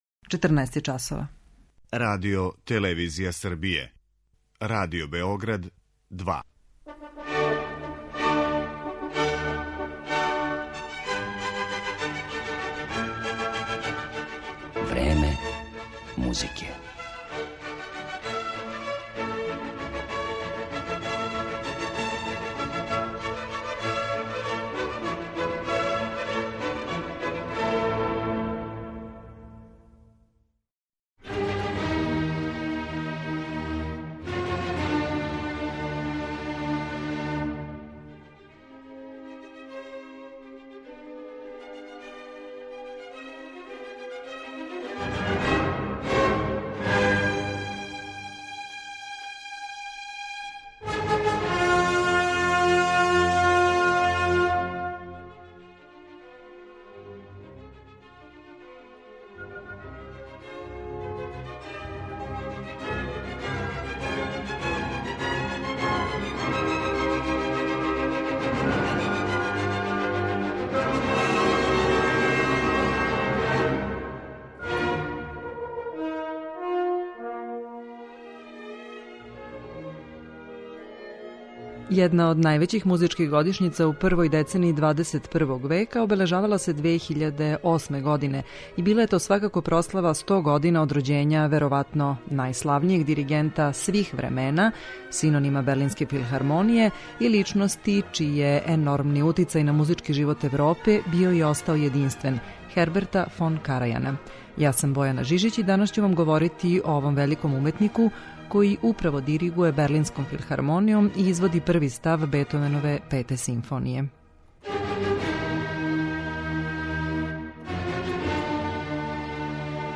Вероватно најславнијем диригенту свих времена, синониму за Берлинску филхармонију и личности са непоновљивим утицајем на музички живот Европе, Херберту фон Карајану, посвећено је данашње Време музике. Поред изврсних уметникових тумачења пре свега немачке музике, моћи ћете да чујете и интервју са Карајаном који је објављен у британском музичком часопису „Грамофон" 2008. године, када је обележавано 100 година од рођења овог великог диригента.